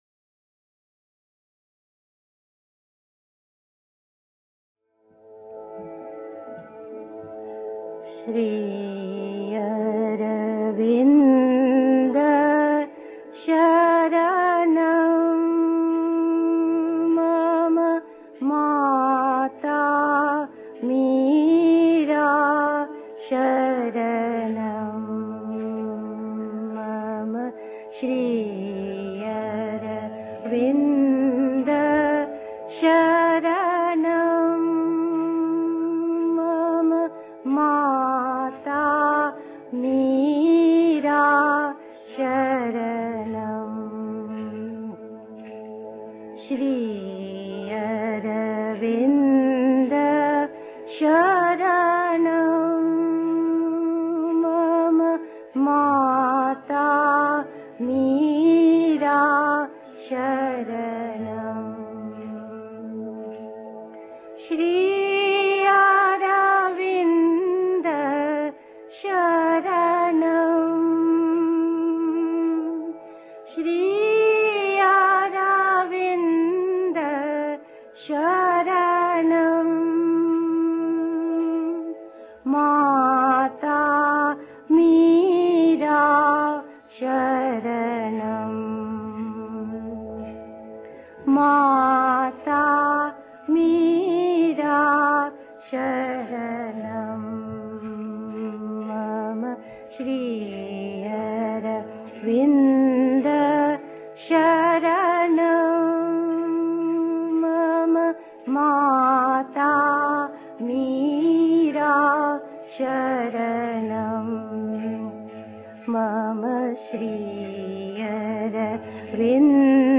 1. Einstimmung mit Musik. 2. Das Prinzip dieses Yoga: Sich dem Göttlichen Einfluss öffnen (Sri Aurobindo, SABCL, Vol. 23, pp. 604-05) 3. Zwölf Minuten Stille.